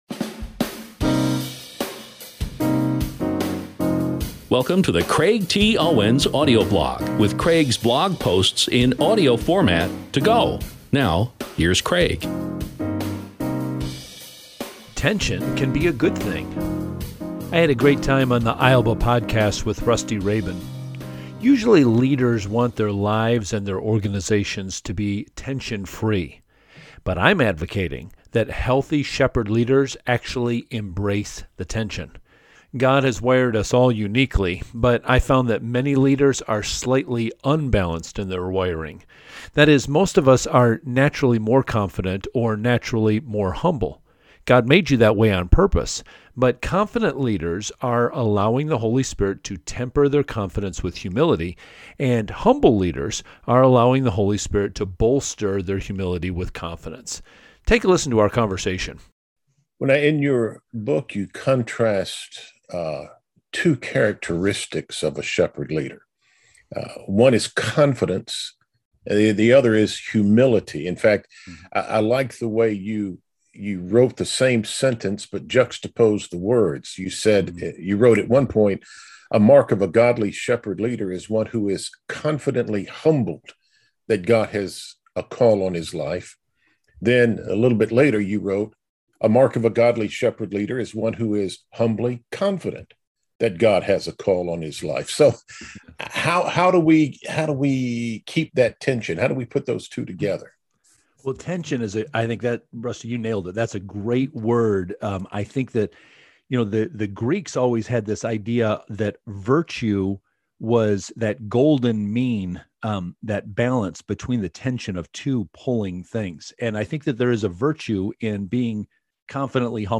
I’ll be sharing more clips from this interview soon, so please stay tuned.